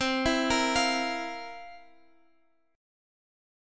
C11 Chord
Listen to C11 strummed